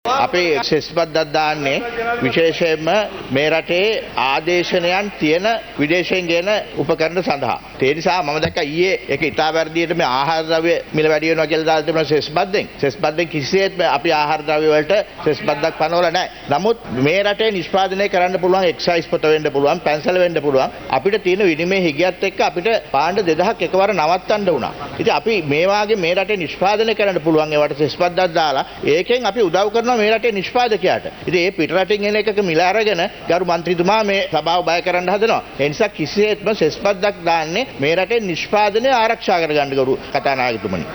මේ ඒ සඳහා පිළිතුරු ලබාදුන් මුදල් රාජ්‍ය අමාත්‍ය රංජිත් සියඹලාපිටිය මහතා.